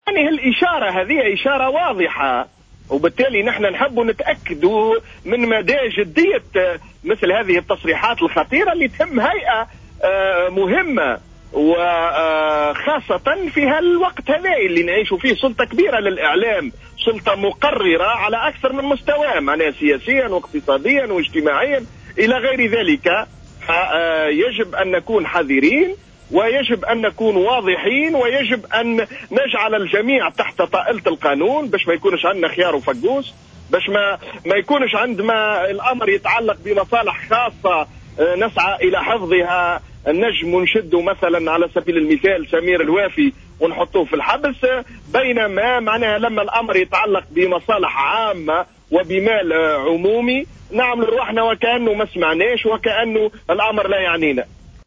دعا النائب بمجلس نواب الشعب خالد شوكات ضيف برنامج "بوليتيكا" إلى ضرورة فتح تحقيق في أسباب استقالة العضوين رشيدة النيفر ورياض الفرجاني من الهيئة العليا المستقلة للاتصال السمعي البصري.